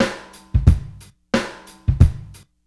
Brooklyn beat b 88bpm.wav